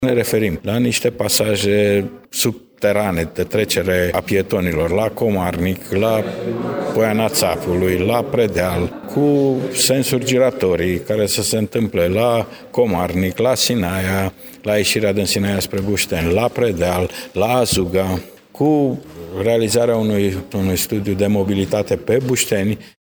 Mihail Veştea, secretar de Stat în cadrul Ministerului Lucrărilor Publice, Dezvoltării și Administrației: